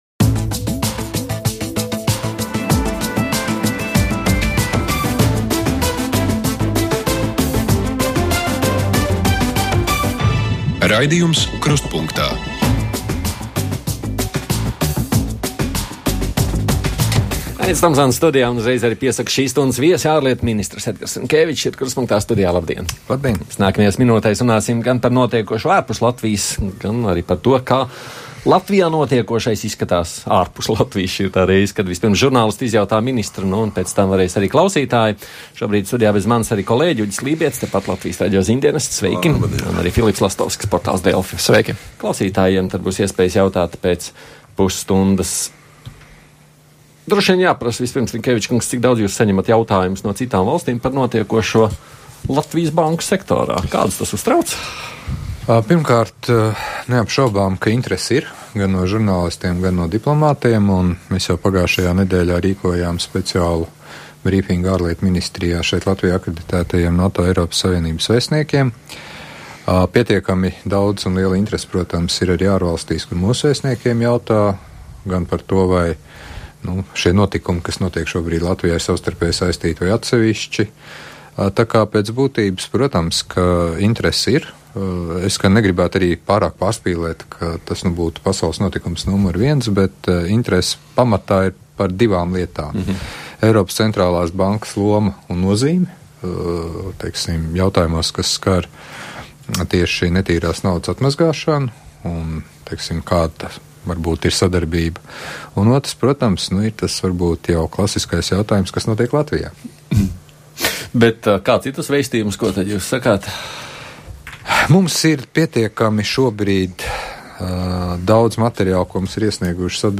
Uz žurnālistu un klausītāju jautājumiem atbild ārlietu ministrs Edgars Rinkevičs
Pēc vieniem studijā būs ārlietu ministrs Edgars Rinkēvičs. Latvijas banku nedienas izskanējušas arī plaši citur pasaulē. Kā viss notiekošais izskatās no citu valstu skatupunkta? Cik daudz ārlietu ministram nākas iesaistīties notiekošajā?